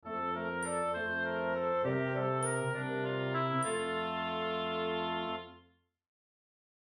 0’29 BPM: 70 Description
Plays end of the track